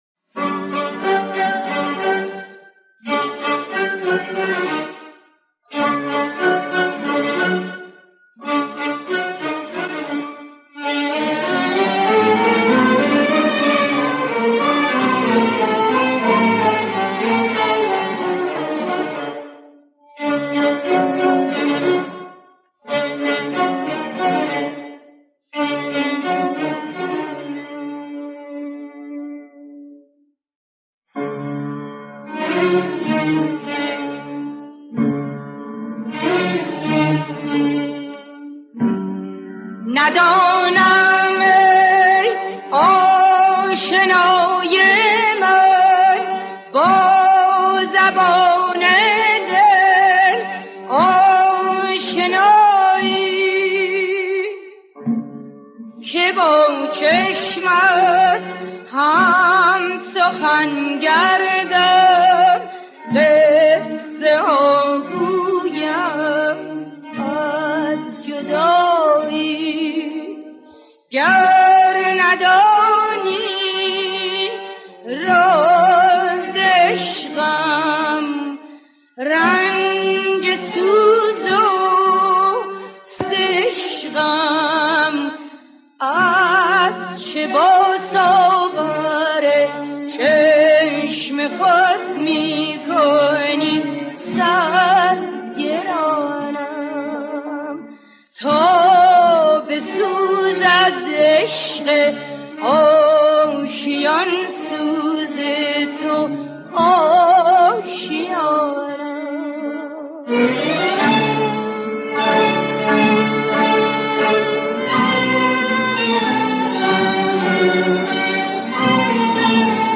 دستگاه: شوشتری / همایون